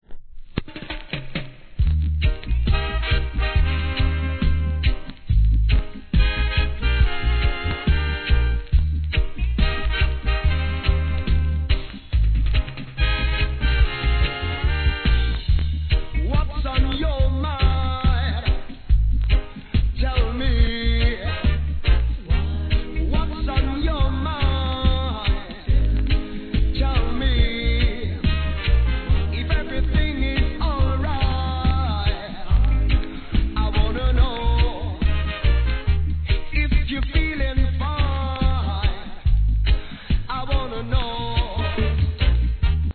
若干チリ入ります
REGGAE